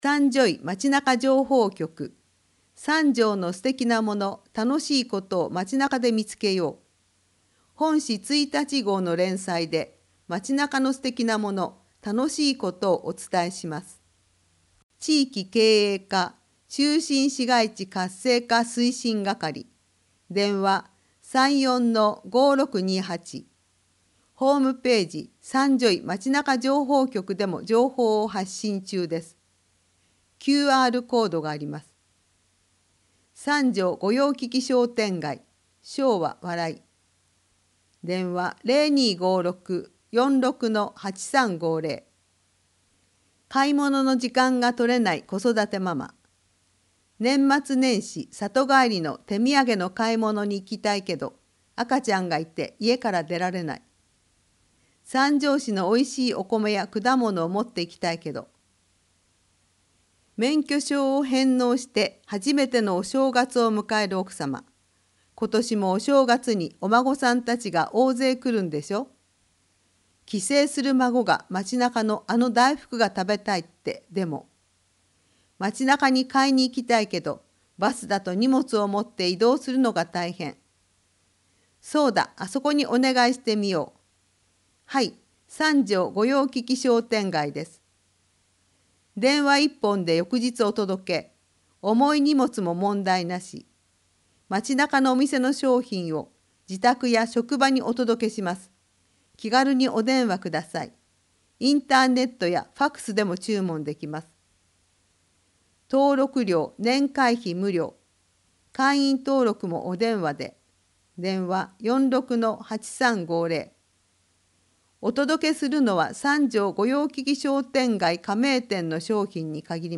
広報さんじょうを音声でお届けします